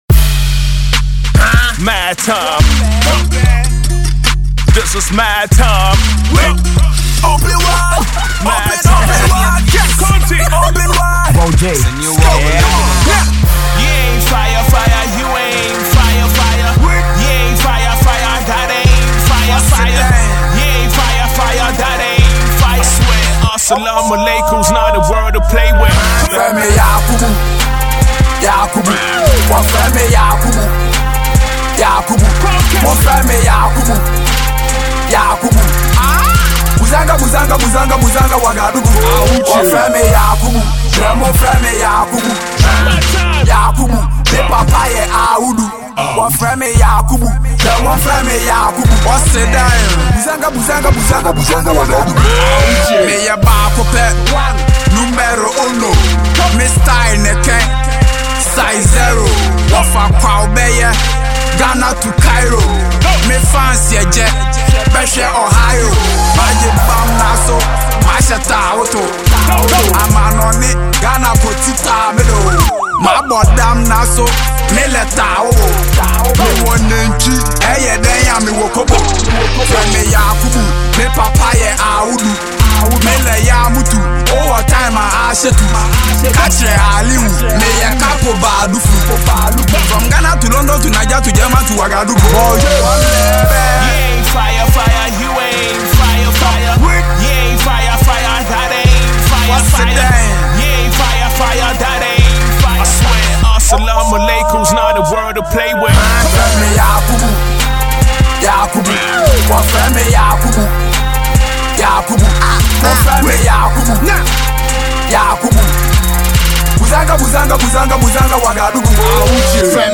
Hip-Hop
Ghanaian Rapper